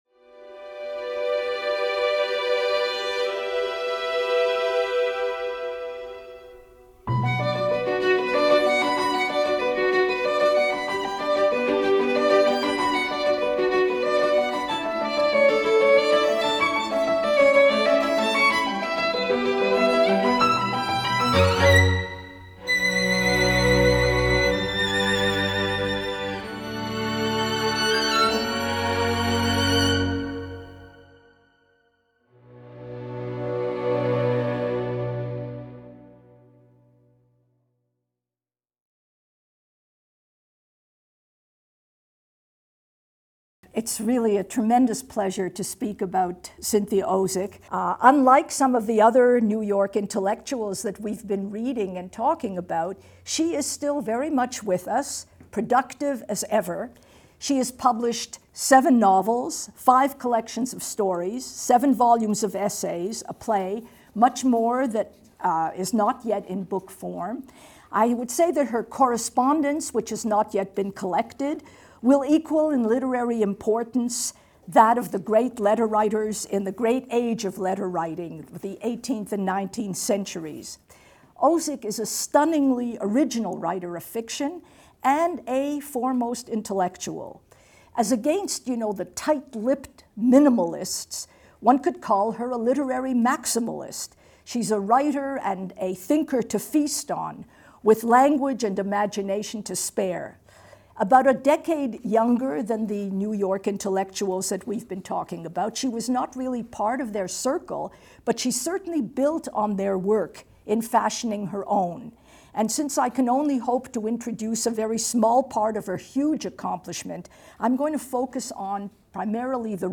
In this lecture, Professor Wisse analyzes the massive intellectual output of Cynthia Ozick.